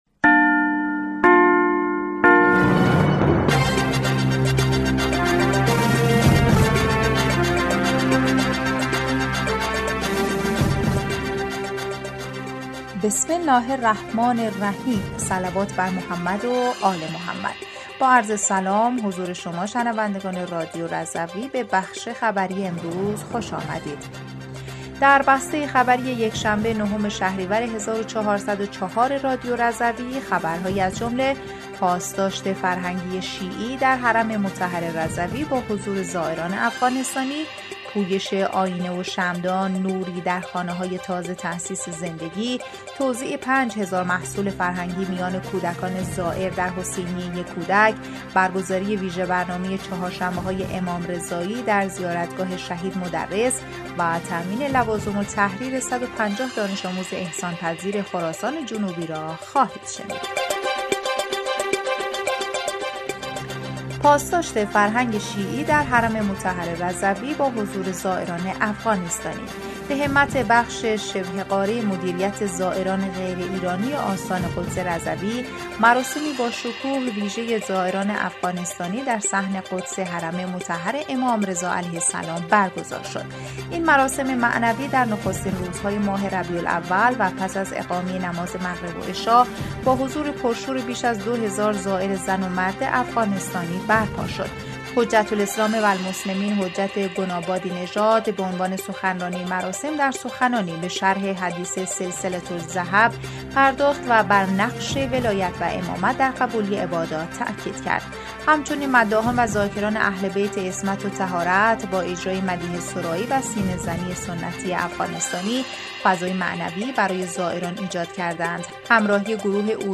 بسته خبری ۹ شهریور ۱۴۰۴ رادیو رضوی/